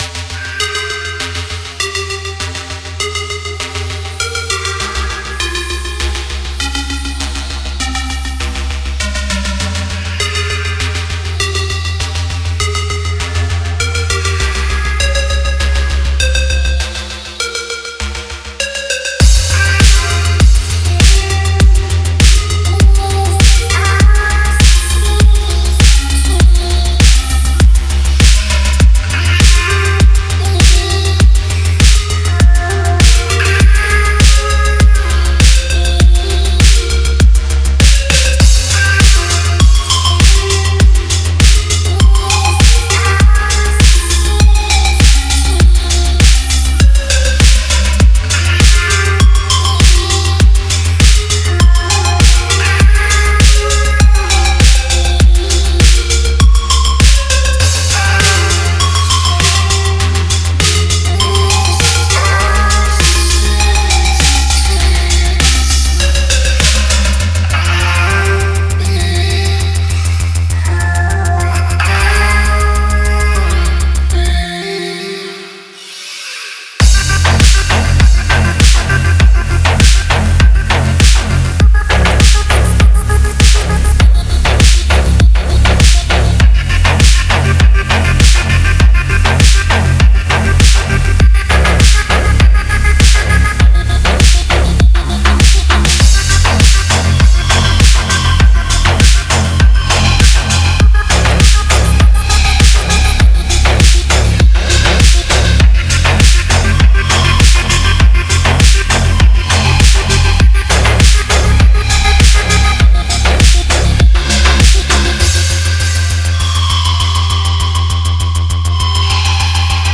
Genre: Witch House.